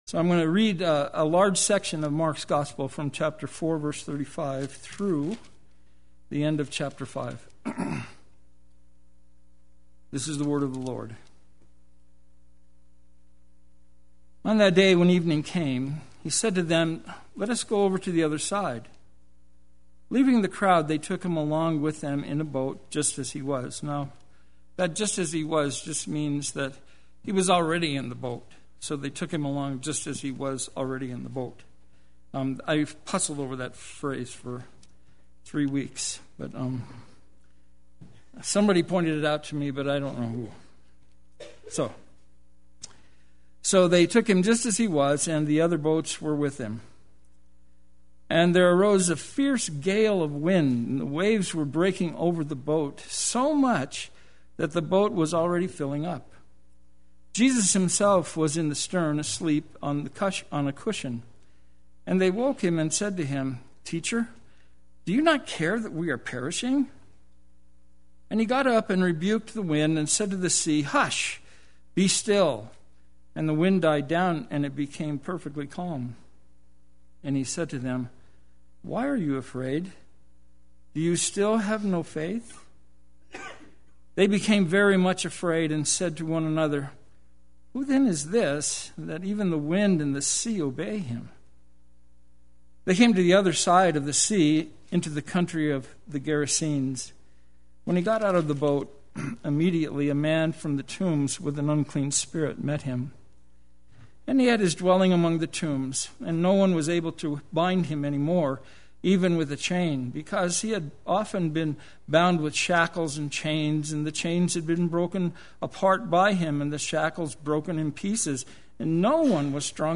Play Sermon Get HCF Teaching Automatically.
The Power of Christ Sunday Worship